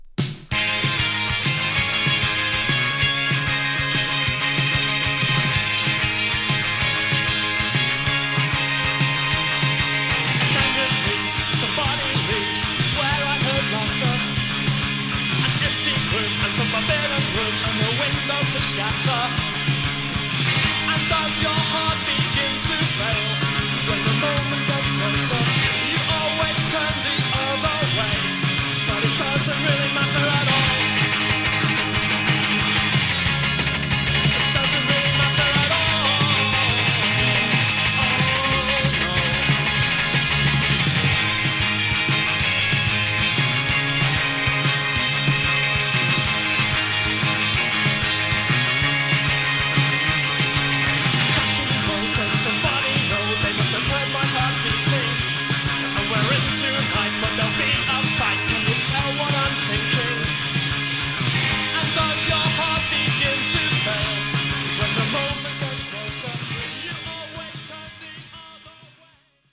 所謂C86象徴的なギター掻き鳴らし系インディー・ロック・サウンド。